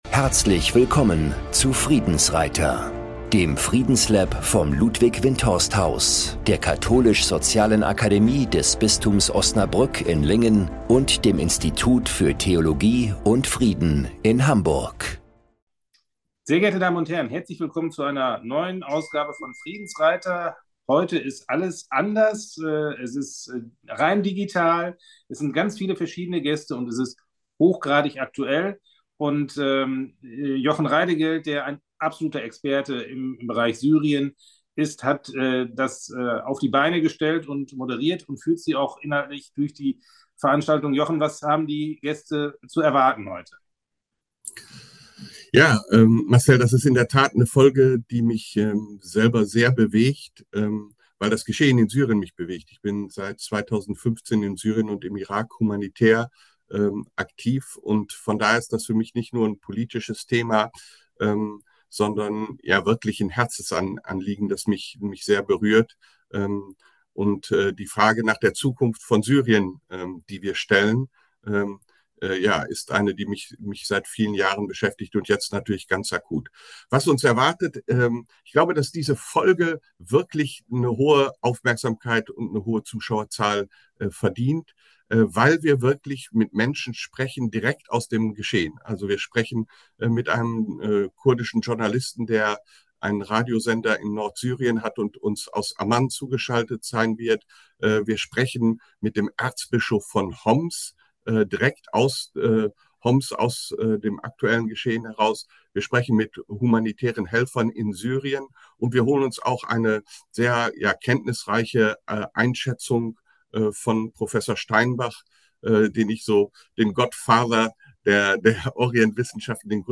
Wir sprechen mit dem Erzbischof von Homs Jacques Mourad